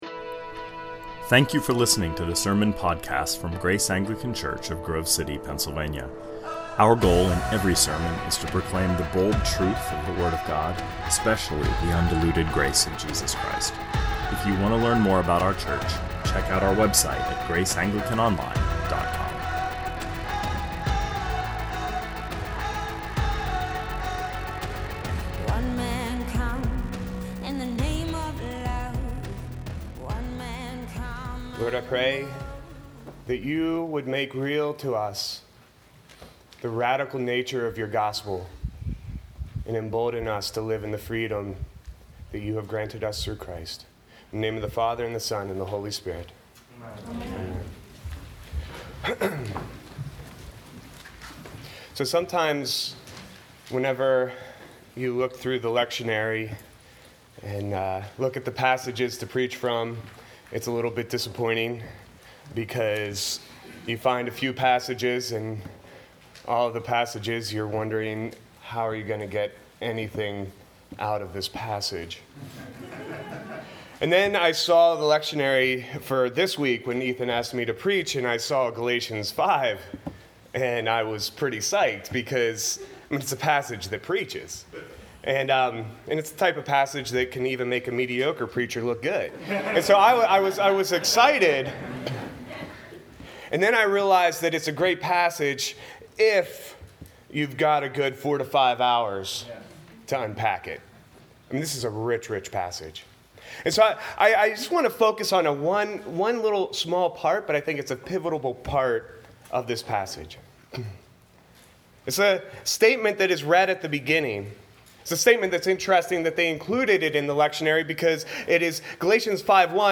2019 Sermons